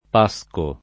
p#Sko] s. m.; pl. paschi — forma antiq. o poet. per pascolo; es.: Menò gli armenti al pasco [men0 l’l’ arm%nti al p#Sko] (Leopardi); oggi viva solo nel nome del Monte dei Paschi di Siena